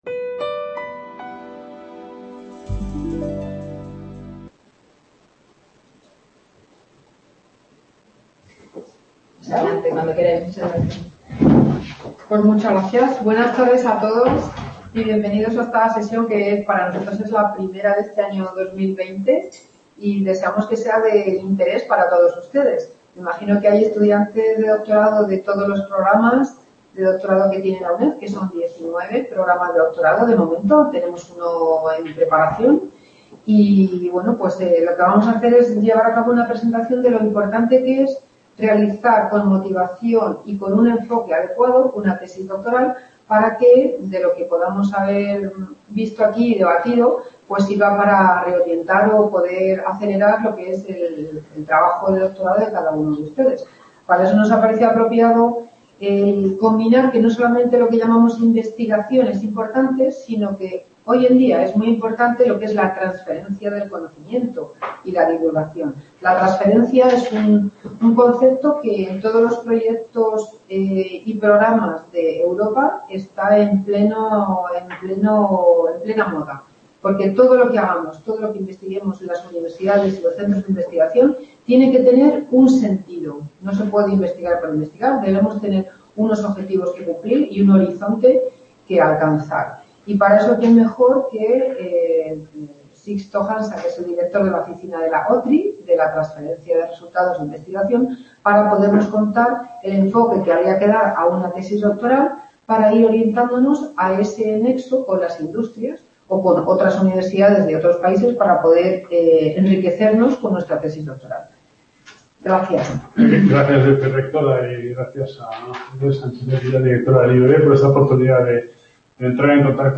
Vicerrec Description Webconferencia.